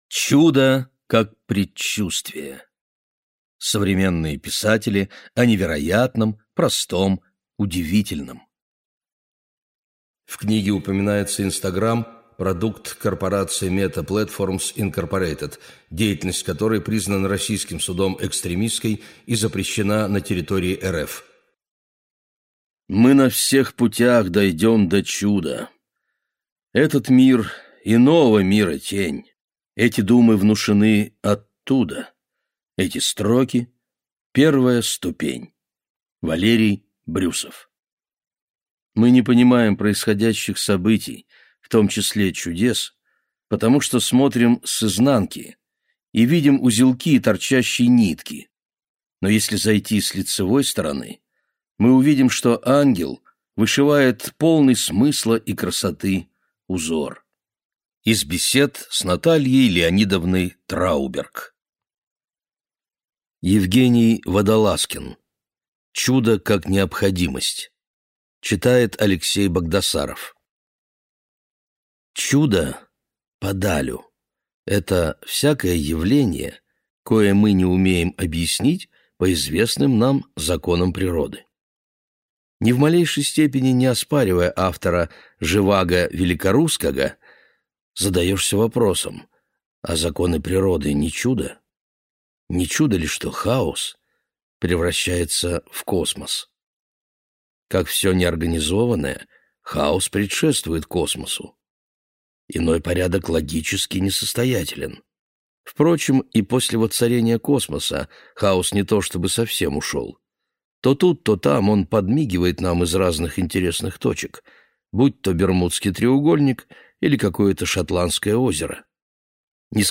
Неожиданный наследник 4 (слушать аудиокнигу бесплатно) - автор Александр Яманов